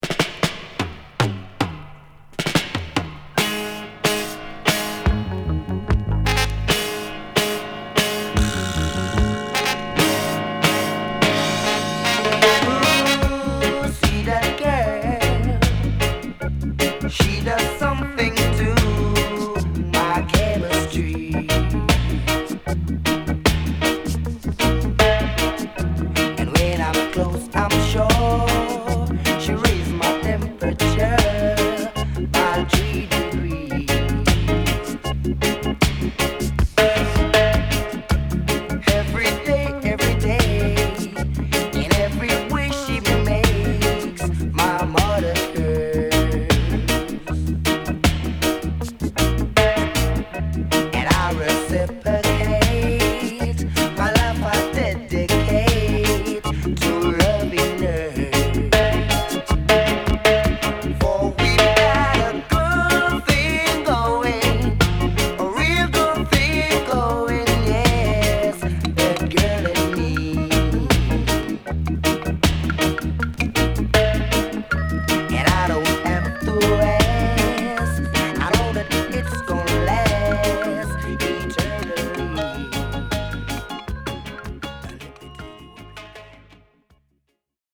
・ 45's REGGAE
Format: 7 Inch